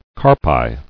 [car·pi]